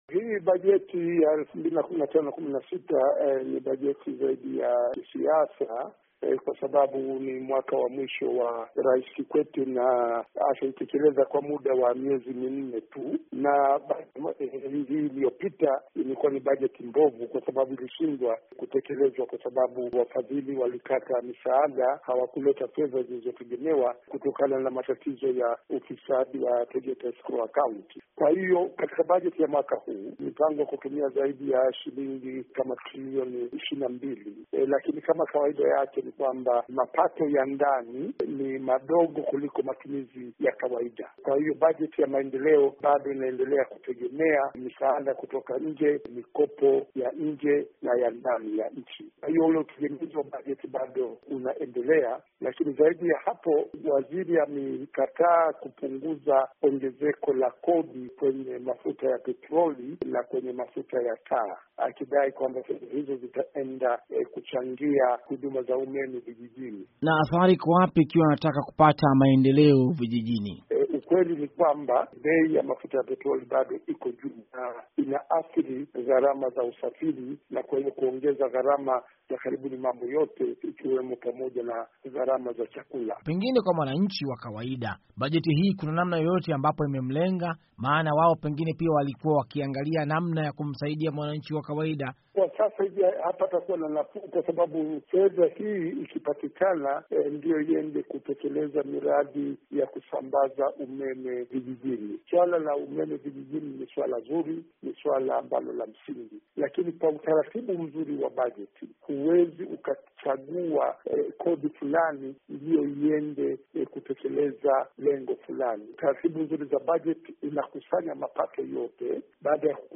Mahojiano na Prof.Lipumba